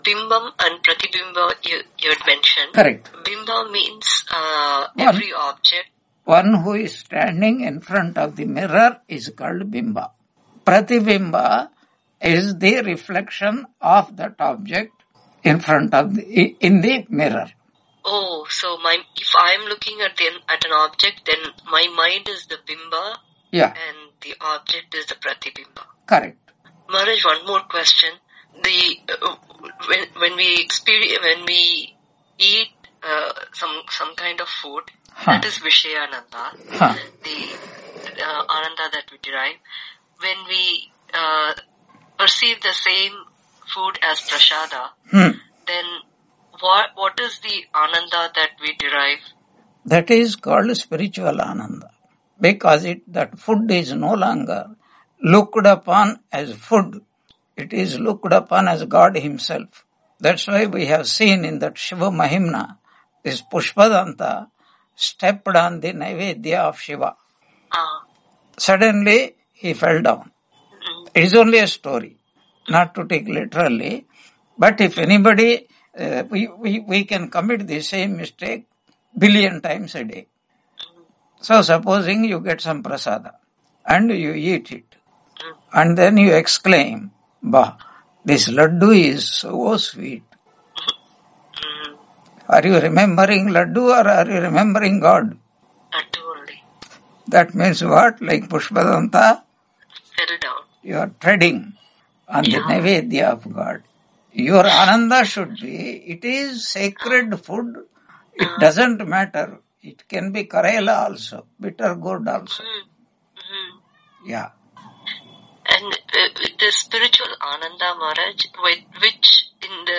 Taittiriya Upanishad Lecture 93 Ch2.8 on 25 February 2026 Q&A - Wiki Vedanta